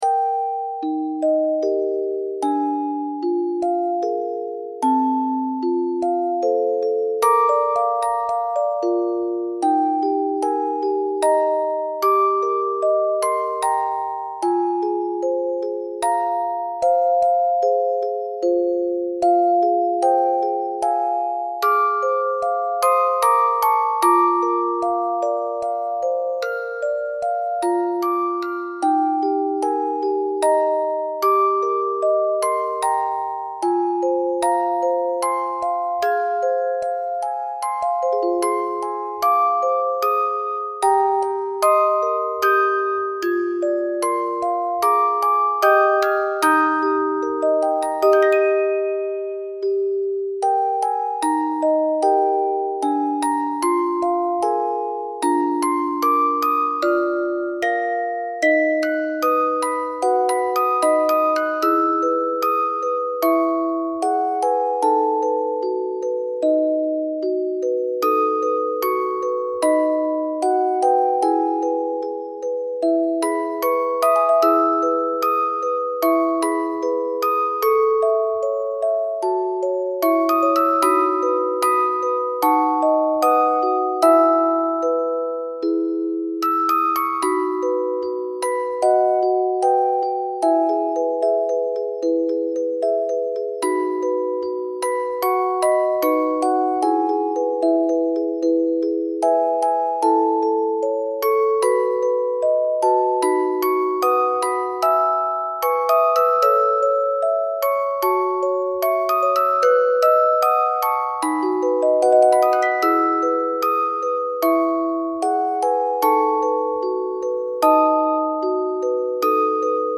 初期作品７曲のメドレー作品です。
８分に及ぶ長いオルゴール曲ですので、リスニングにどうぞ ^^
オープニング＆エンディングと、曲間の繋ぎのフレーズはメドレー制作時に書き下ろしてあります。
※リテイクに合わせて、テンポを調整。